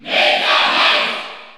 Crowd cheers (SSBU) You cannot overwrite this file.
Meta_Knight_Cheer_French_PAL_SSBU.ogg